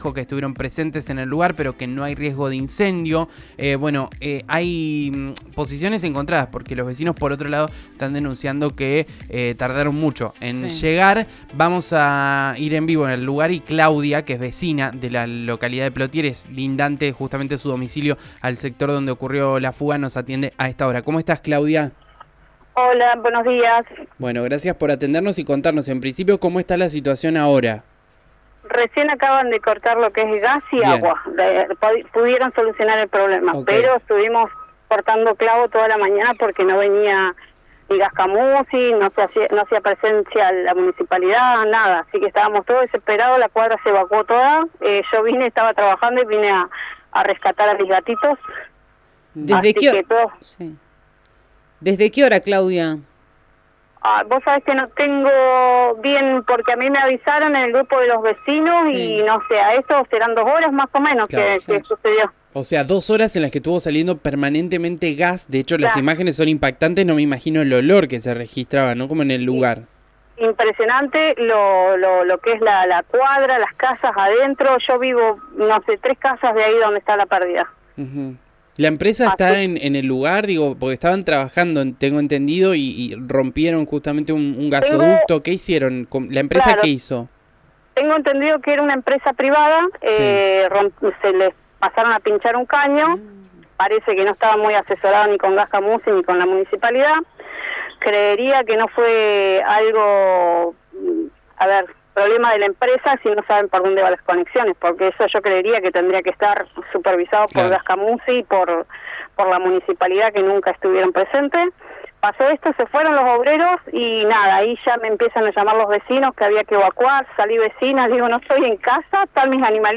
En diálogo con RIO NEGRO RADIO